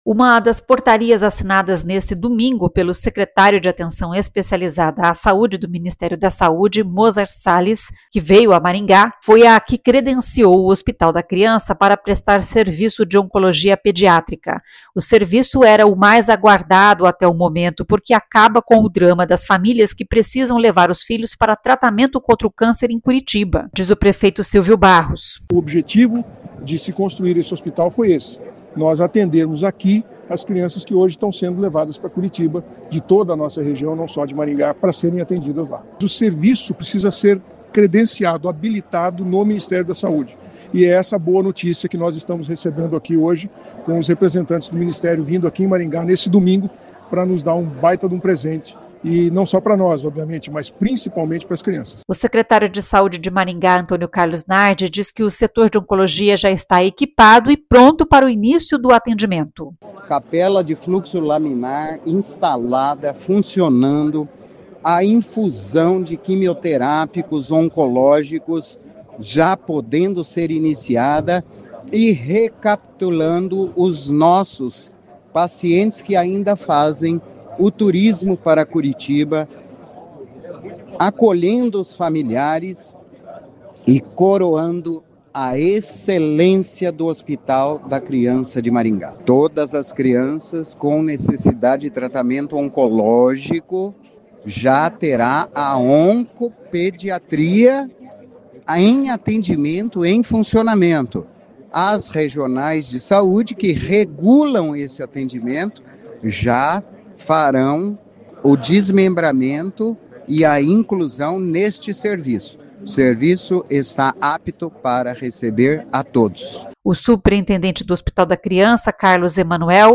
O serviço era o mais aguardado até o momento, porque acaba com o drama das famílias que precisam levar os filhos para tratamento contra o câncer em Curitiba, diz o prefeito Sílvio Barros.